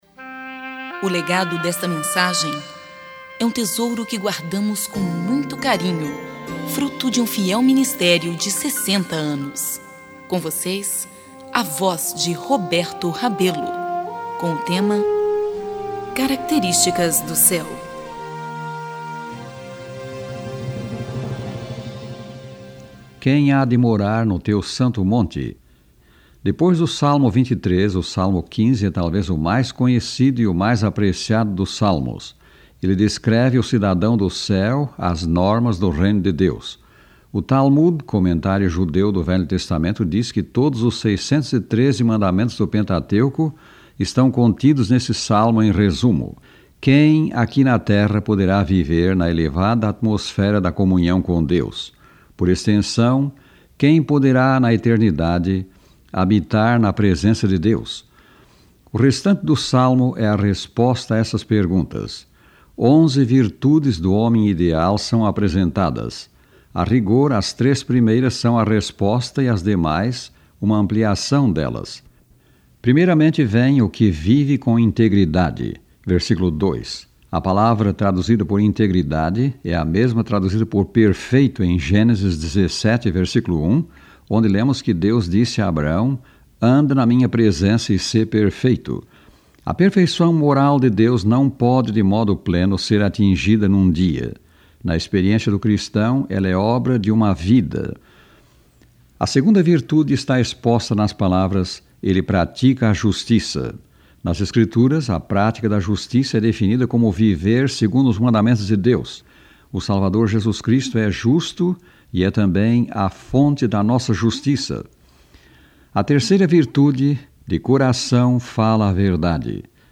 Palestra 011